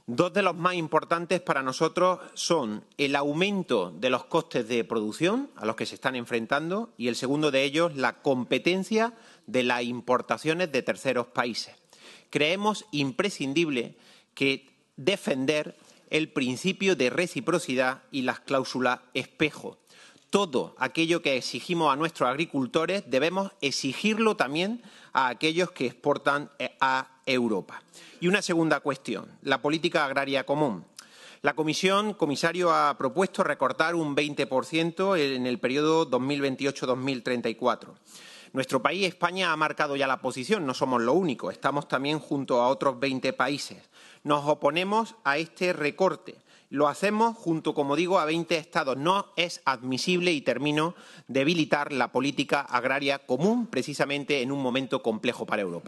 Cortes de sonido # Jose Latorre
Jose-Latorre-Parlamento-Europeo.mp3